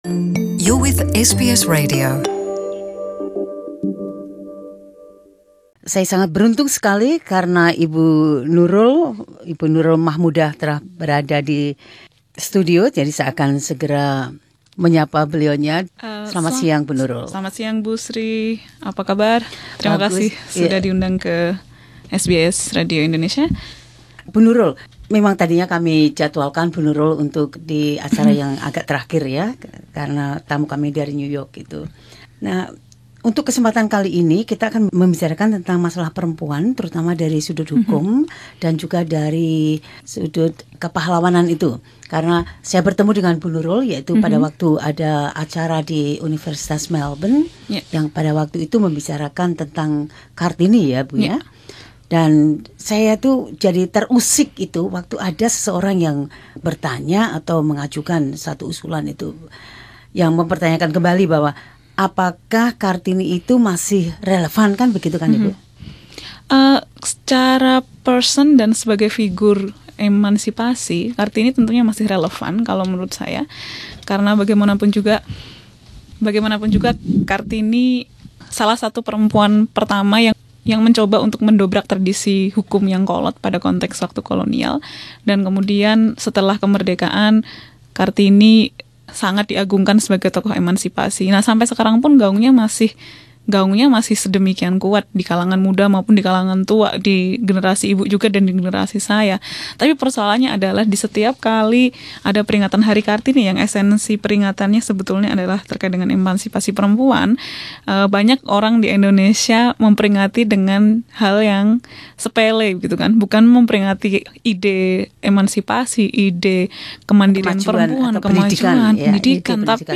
in the SBS studio.